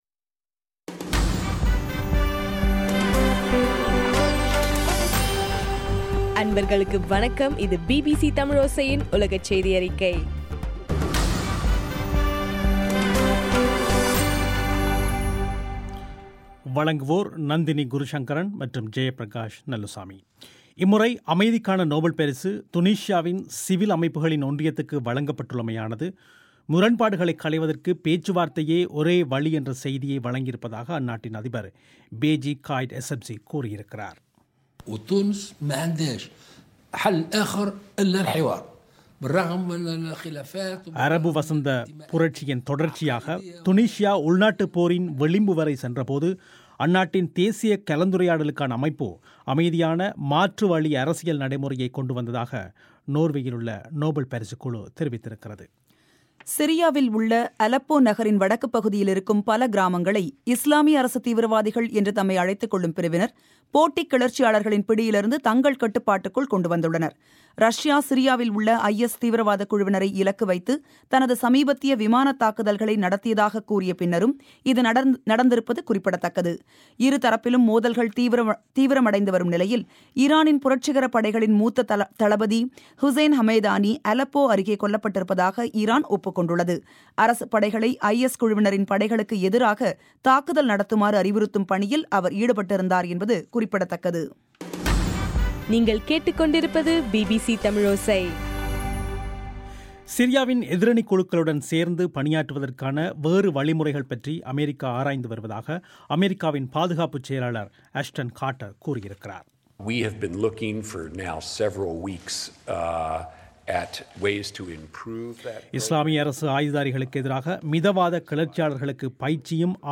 அக்டோபர் 9, 2015 பிபிசி தமிழோசையின் உலகச் செய்திகள்